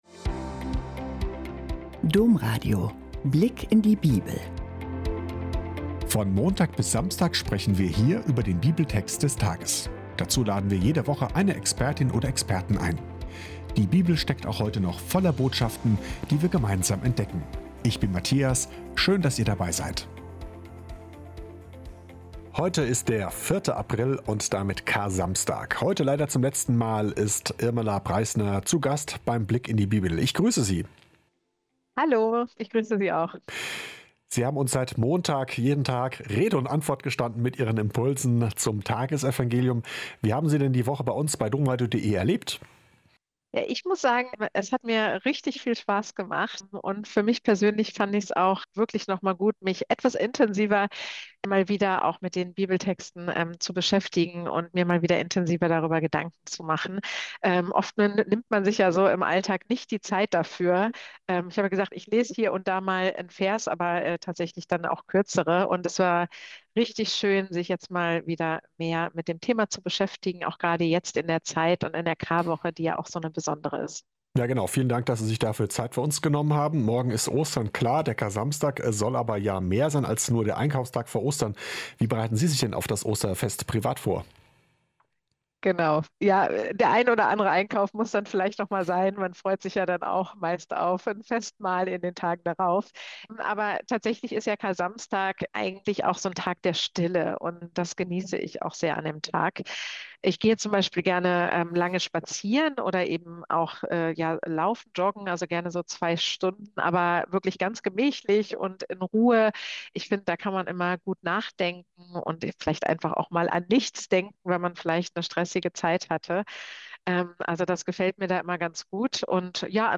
Eine ruhige, tiefgehende Folge, die Hoffnung schenkt und neugierig macht.